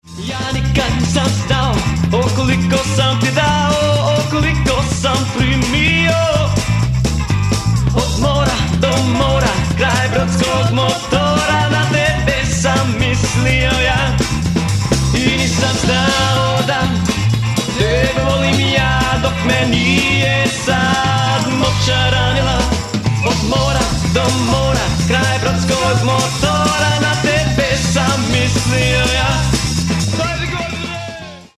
Mixano u "Profile Studios" Vancouver BC
u "Slanina Studios" Vancouver BC...